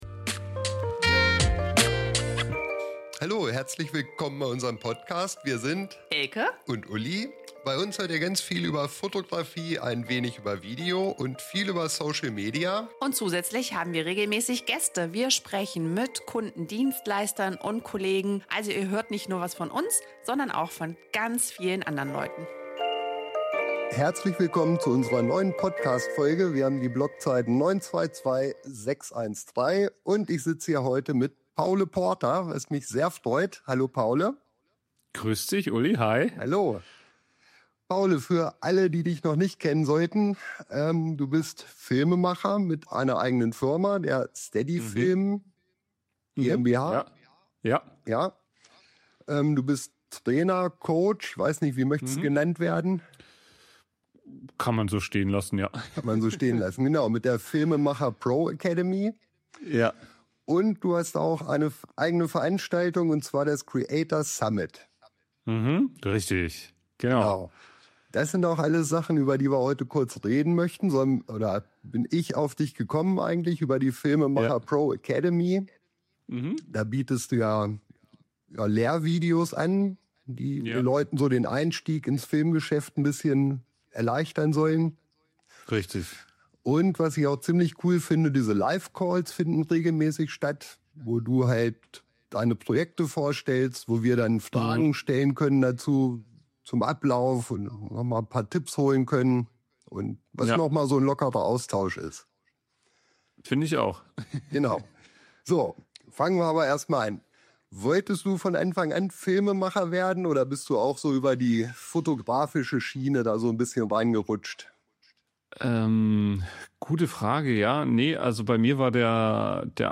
Hier sind einige Highlights und spannende Einblicke aus unserem Gespräch: